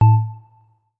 Melodic Power On 5.wav